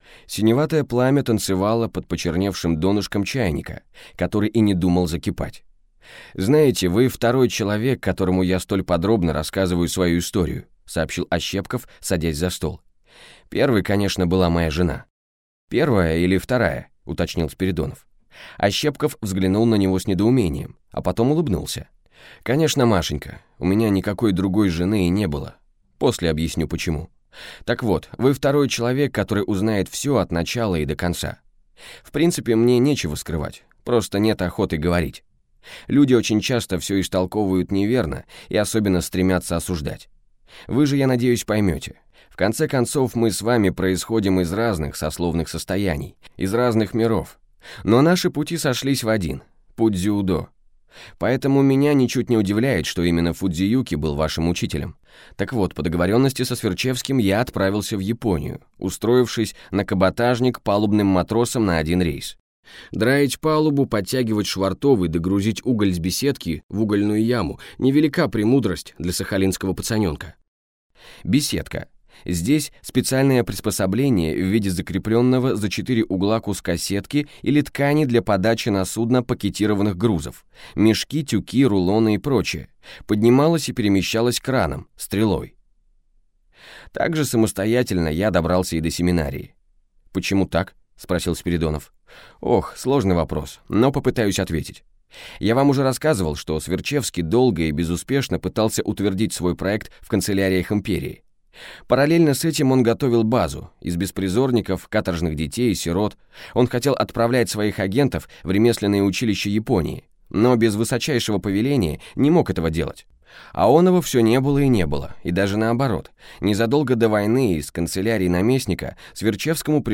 Аудиокнига Белый квадрат. Захват судьбы | Библиотека аудиокниг